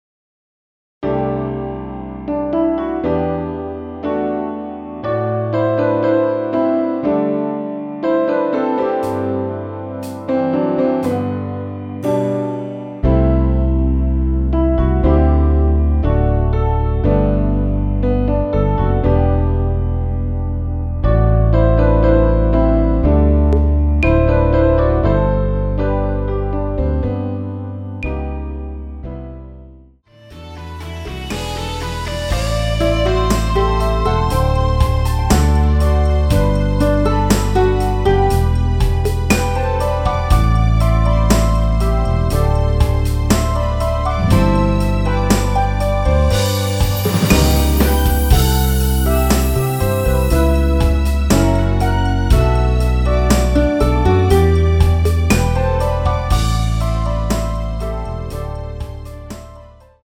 전주없이 노래가 바로 시작 되는 곡이라서 전주 만들어 놓았습니다.
9초부터 하이햇 소리 4박째 노래 시작 하시면 됩니다.
앞부분30초, 뒷부분30초씩 편집해서 올려 드리고 있습니다.